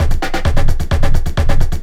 Percussion 15.wav